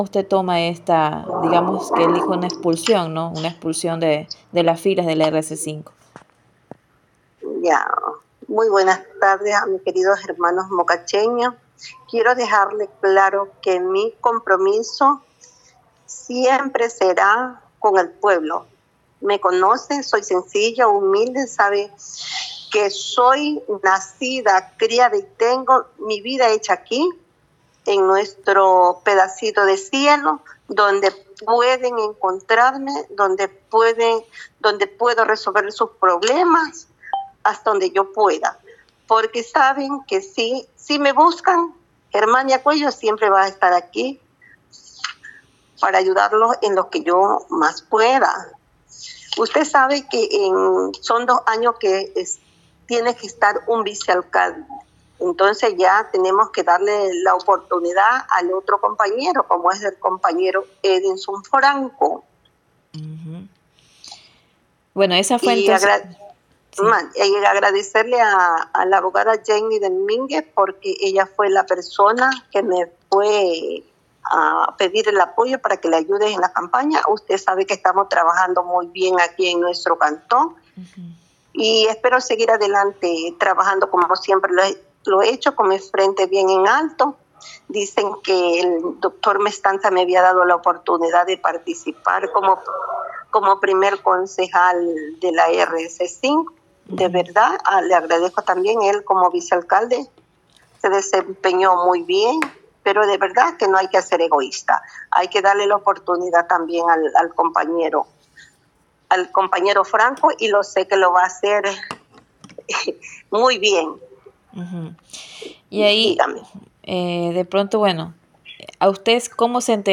Coello se pronunció al respecto en una entrevista con diario ALDIA.
ENTREVISTA-GERMANIA-COELLO.m4a